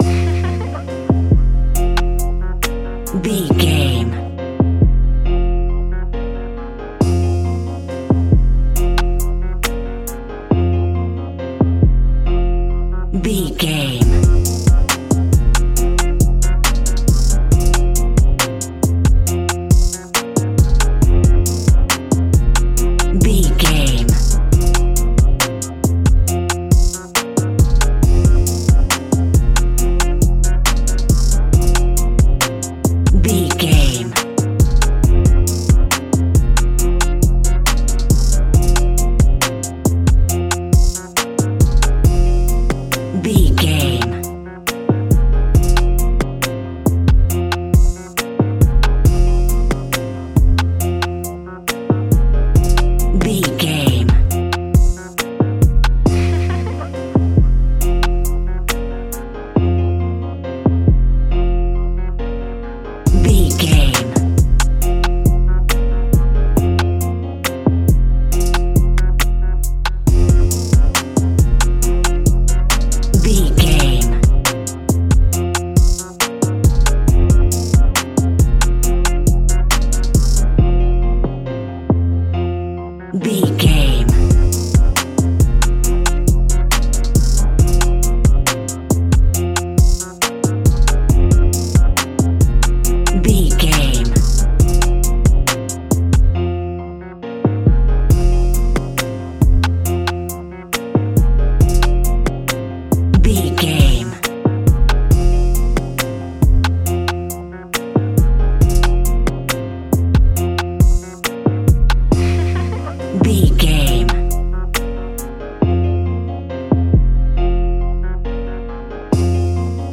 Aeolian/Minor
aggressive
intense
driving
heavy
dark
drum machine
synthesiser